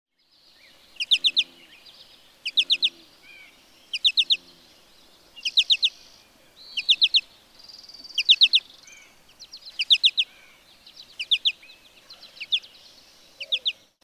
American goldfinch
Call notes in flight, per-CHIK-o-ree, per-CHIK-o-ree.
William L. Finley National Wildlife Refuge, Corvallis, Oregon.
546_American_Goldfinch.mp3